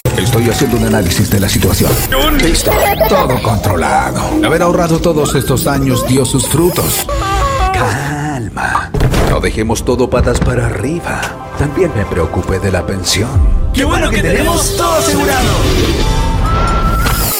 Commerciale, Profonde, Naturelle, Polyvalente, Corporative, Jeune, Senior, Urbaine, Cool, Mature, Amicale